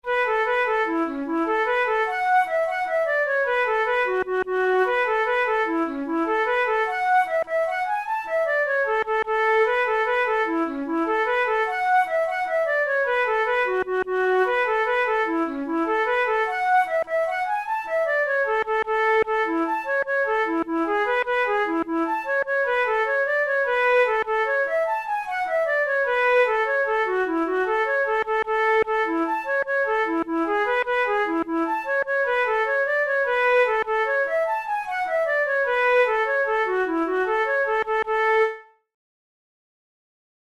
Traditional Irish march
Categories: Celtic Music Jigs Marches Difficulty: easy